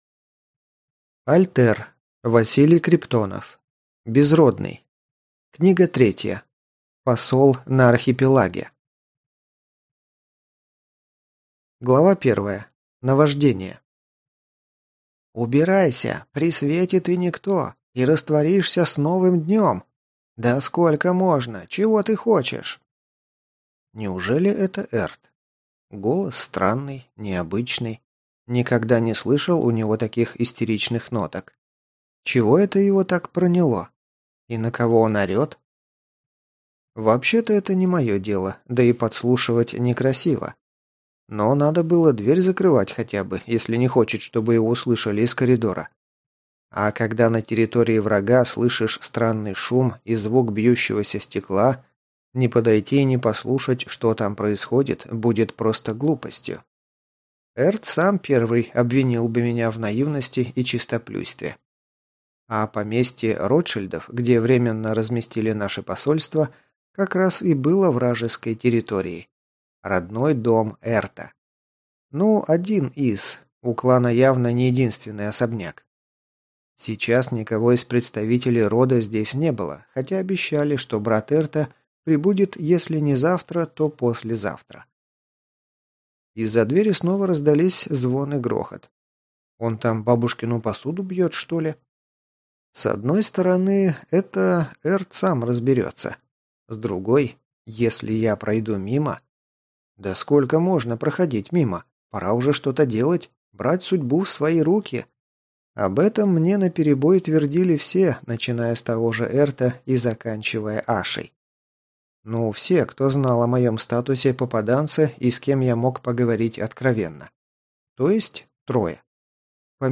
Аудиокнига Посол на Архипелаге | Библиотека аудиокниг